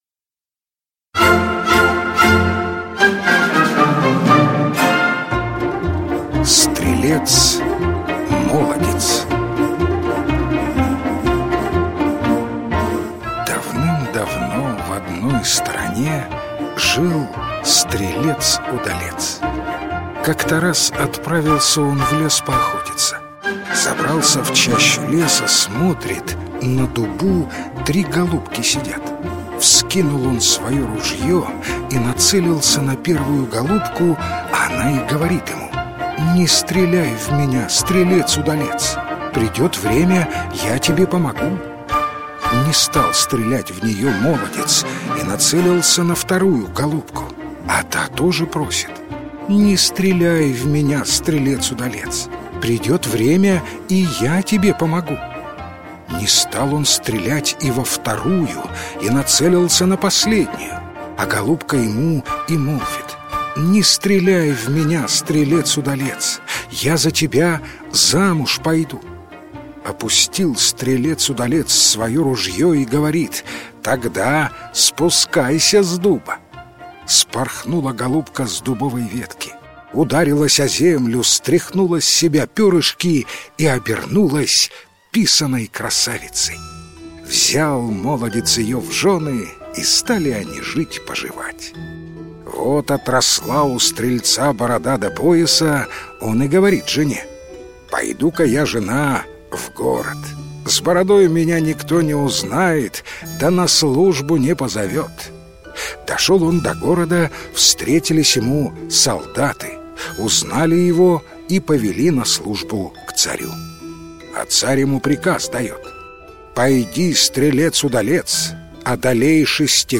Стрелец-молодец - украинская аудиосказка. Пошел стрелец-удалец на охоту и увидел трех голубок. Нацелился на первую, но она заговорила с ним.